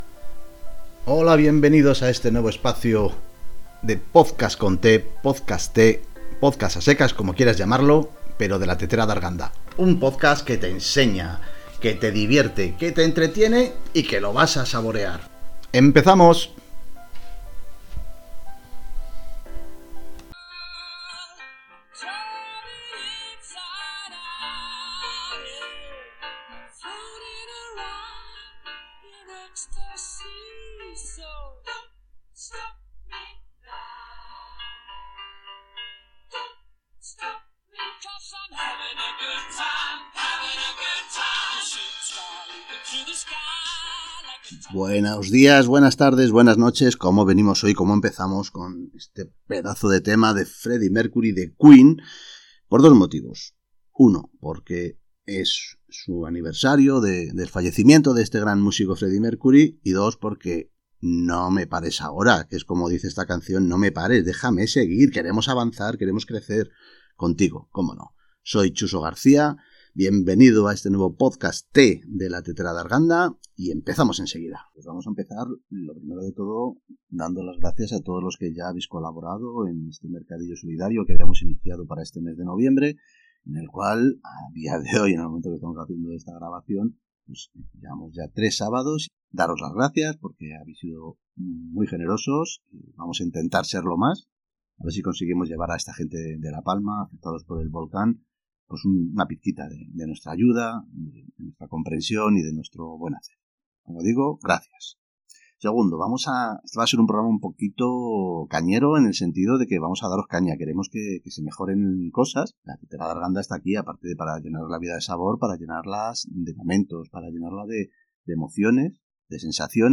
Y no os podéis perder la entrevista que hacemos con nuestra nueva colaboradora